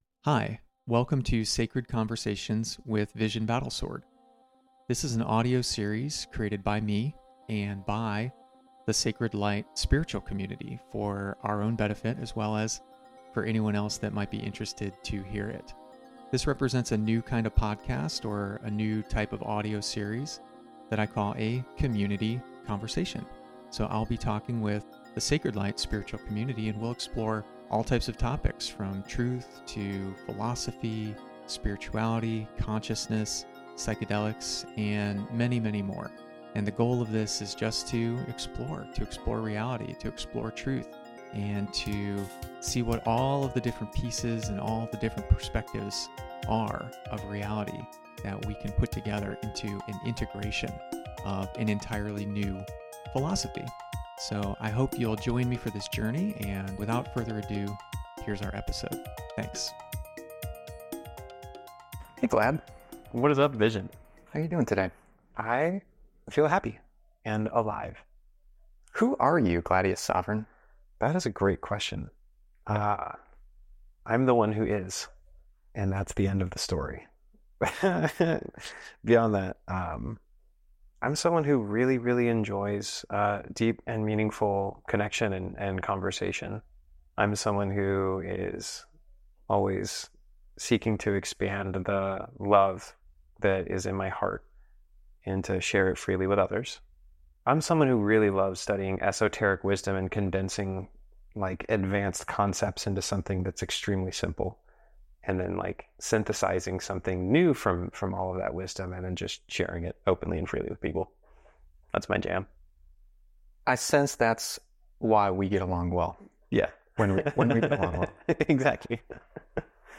conversation11-acceptance.mp3